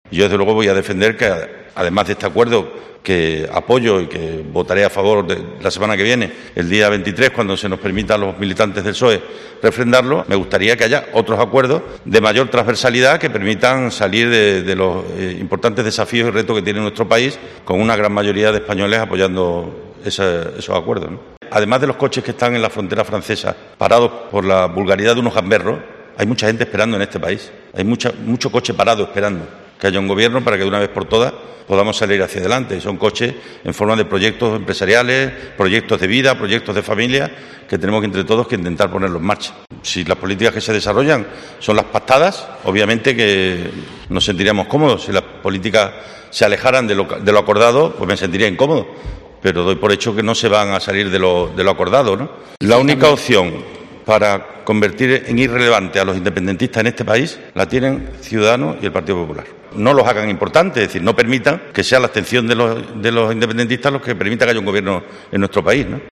El presidente de la Junta ha realizado estas declaraciones a preguntas de los medios tras firmar en la sede de la Presidencia, en Mérida, con otras instituciones públicas y entidades del sector el Acuerdo Estratégico para el Fomento del Autoconsumo Eléctrico en Extremadura.